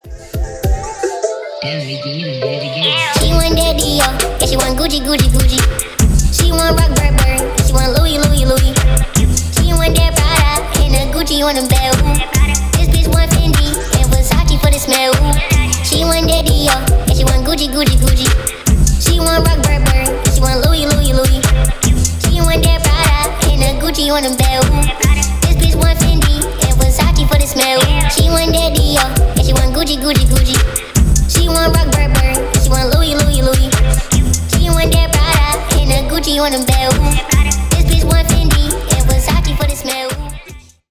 Ремикс # Рэп и Хип Хоп
весёлые